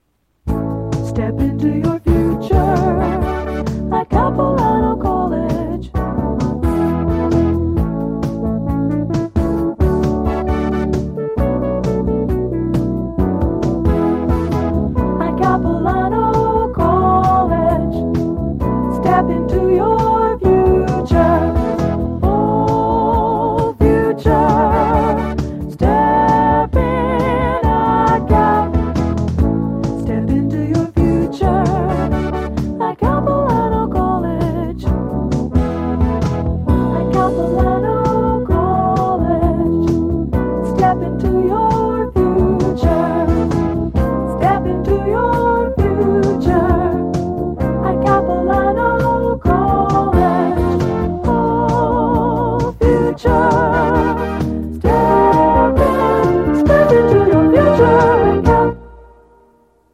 audio cassette